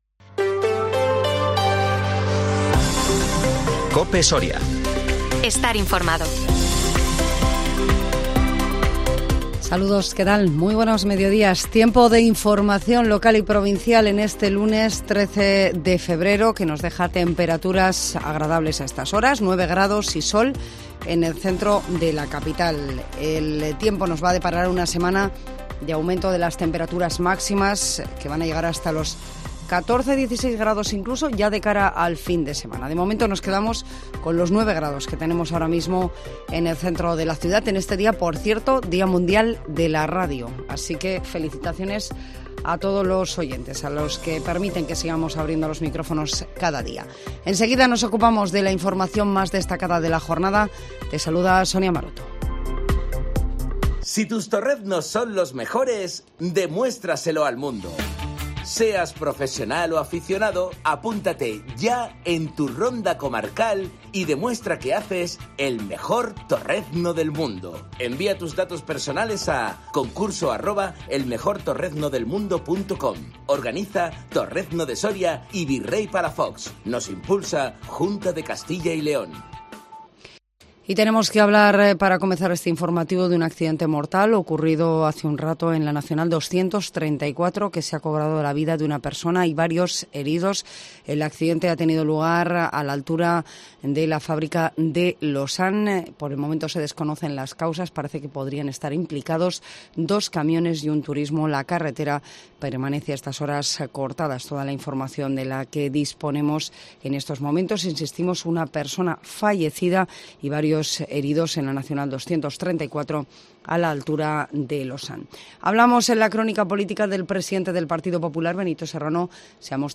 INFORMATIVO MEDIODÍA COPE SORIA 13 FEBRERO 2023